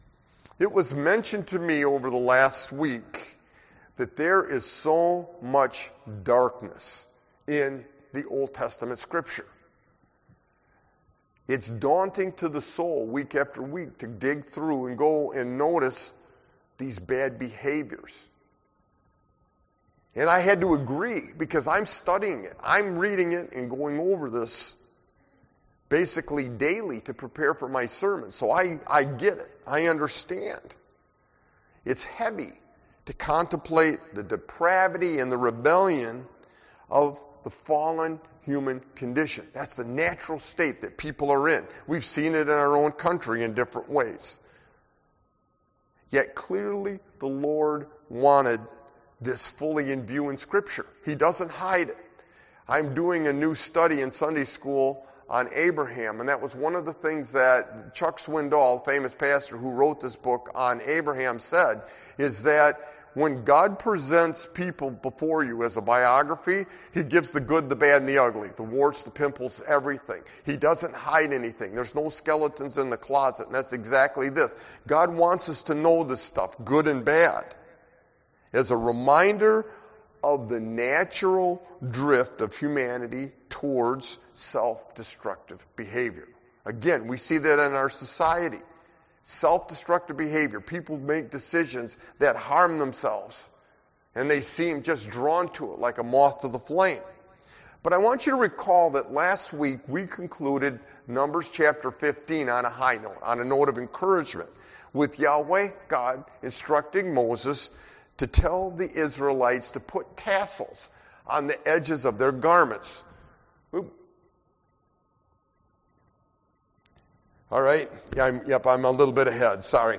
Sermon-Light-and-Darkness-LXVI-62021.mp3